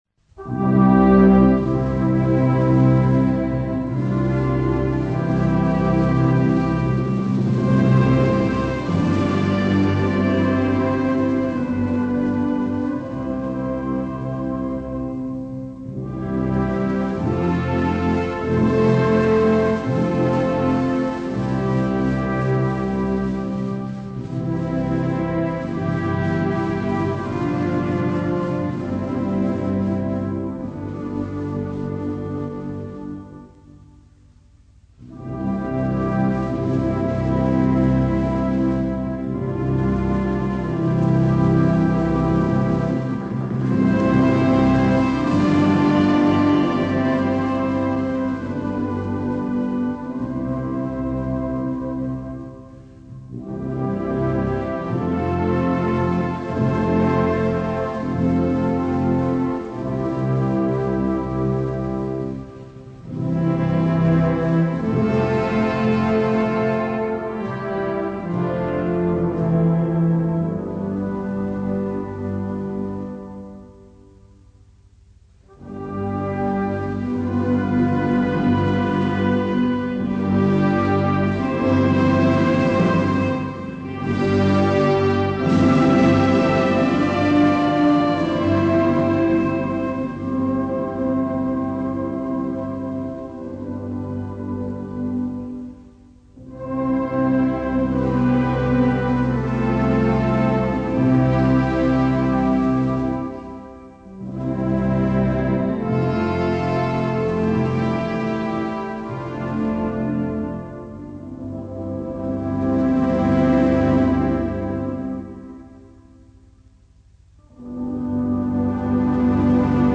Послушайте оркестровое исполнение всего лишь одного куплета «Коль славен» и проникнитесь этой чудесной мелодией:
kol_slaven-instr.mp3